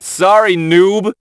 hotshot_kill_04.wav